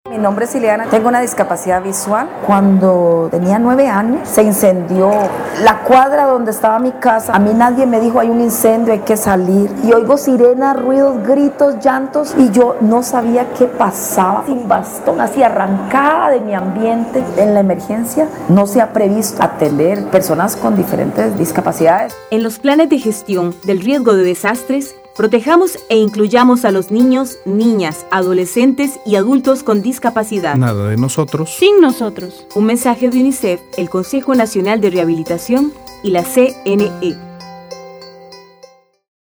[Anuncio de radio].